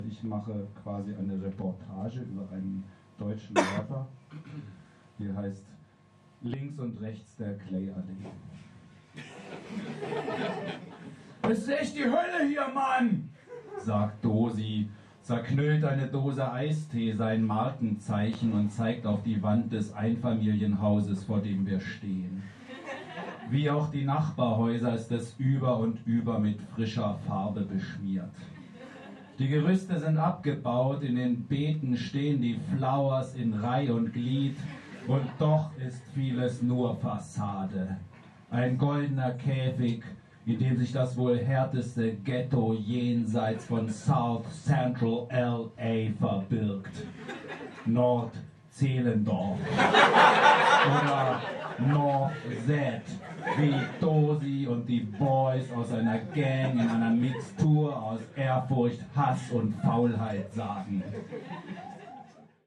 Showdown am Kolle Links und rechts der Clay-Allee Wahrsagerin Liveliteratur in Buchform und als CD …